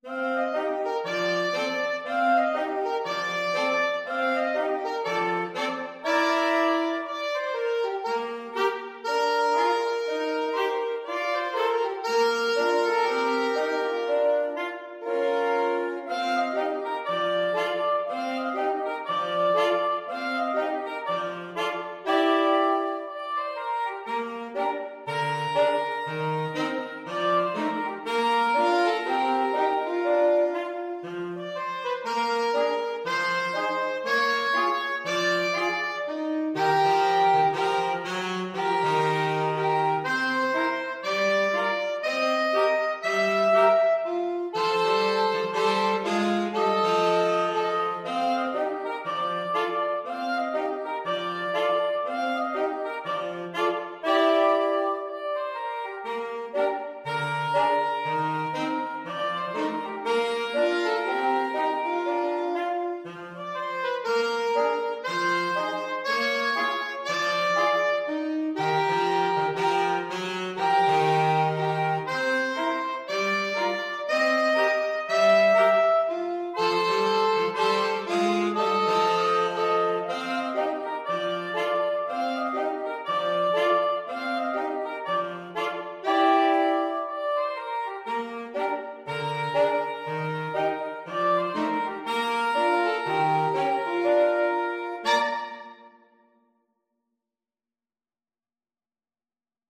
Soprano SaxophoneAlto SaxophoneTenor Saxophone
2/2 (View more 2/2 Music)
Allegro (View more music marked Allegro)
Woodwind Trio  (View more Intermediate Woodwind Trio Music)
Jazz (View more Jazz Woodwind Trio Music)